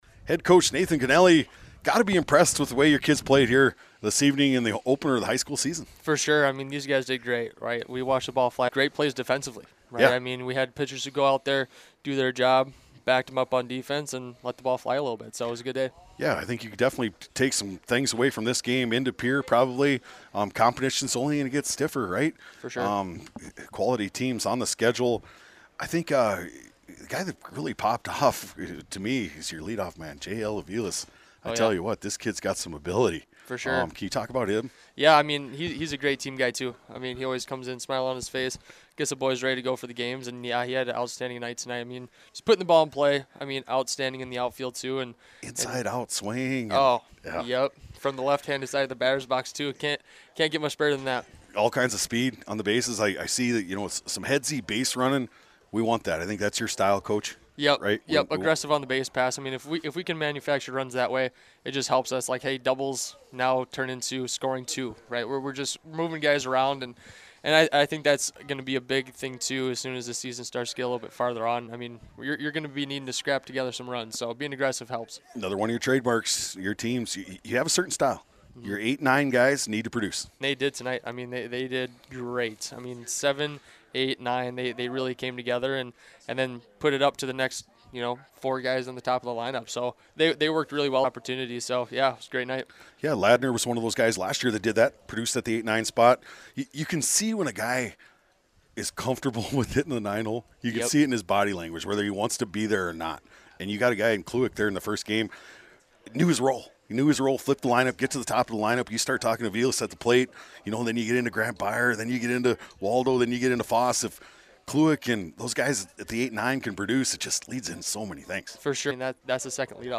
In the postgame discussion